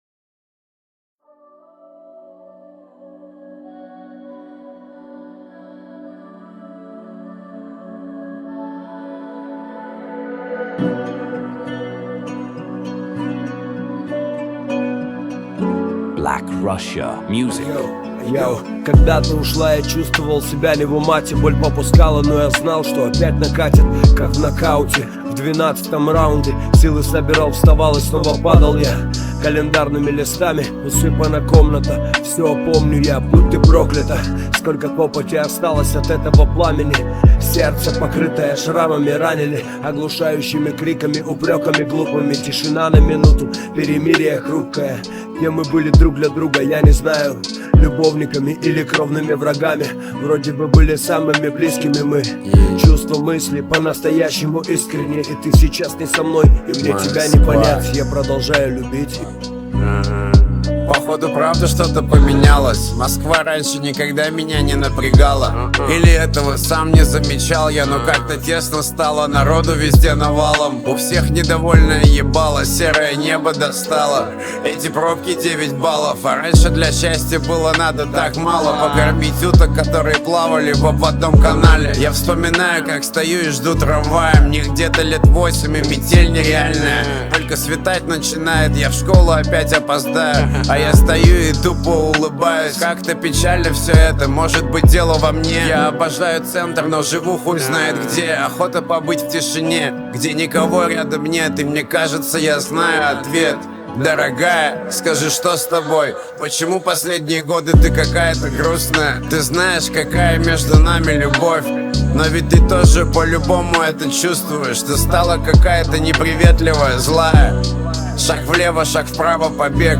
Мэшап ТикТок ремикс